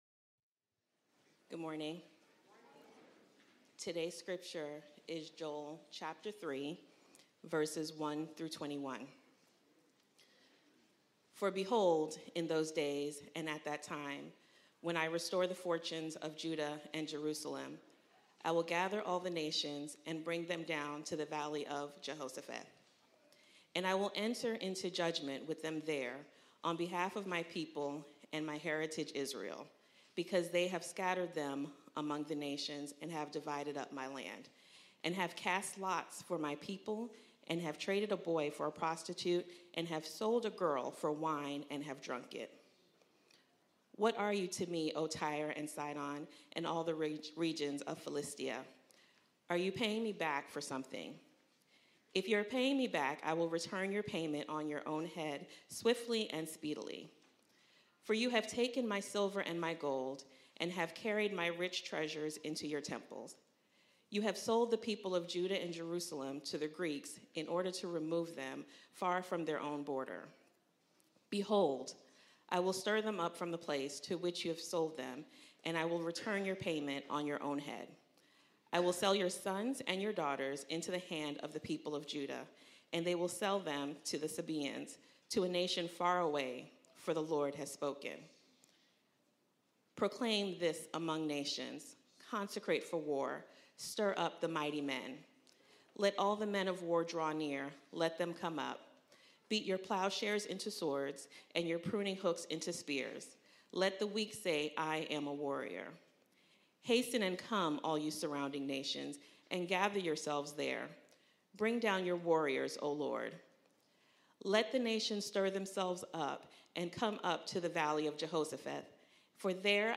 East Point Church Sermons